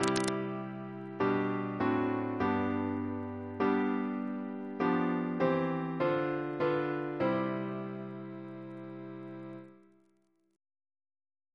Single chant in C Composer: William Savage (1720-1789) Reference psalters: ACP: 247; OCB: 190; PP/SNCB: 166